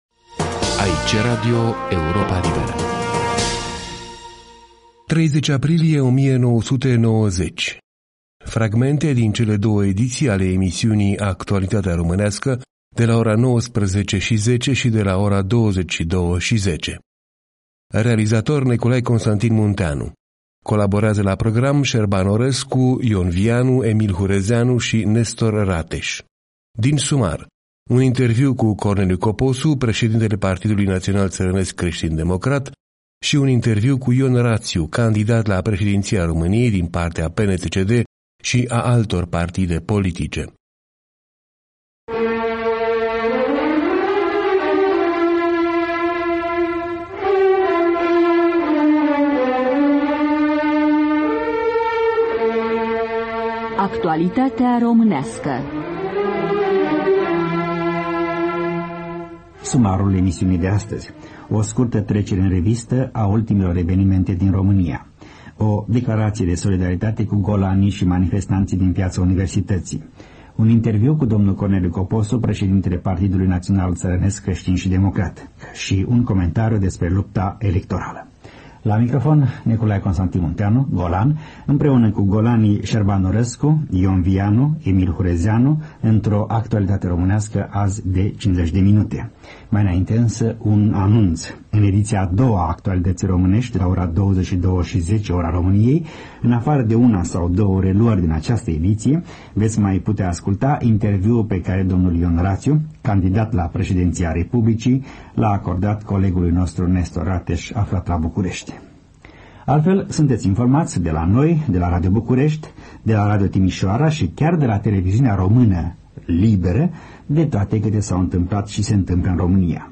30 aprilie 1990: fragmente din cele două ediții ale emisiunii „Actualitatea românească”, de la ora 19:10 și de la ora 22:10.
Din sumar: un interviu cu Corneliu Coposu,...